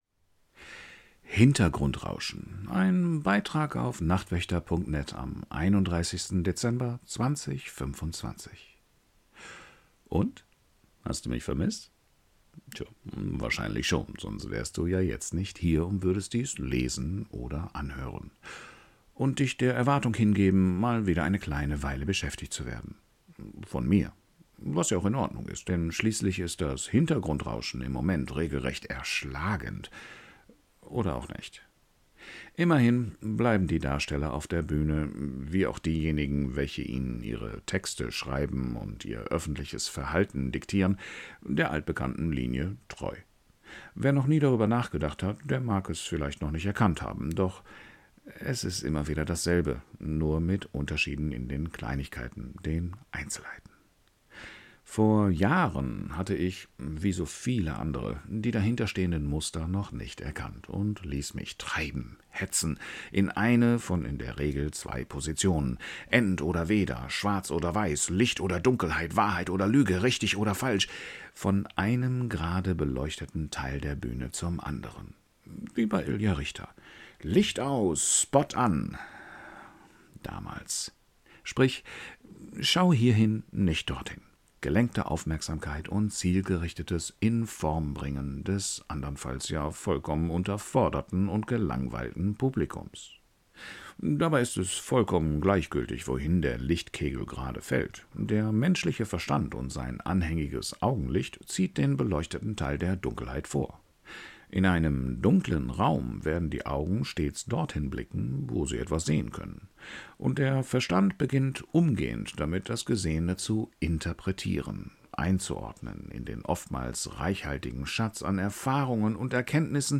Nachfolgender Beitrag im Ton-Format, vorgetragen vom Nachtwächter: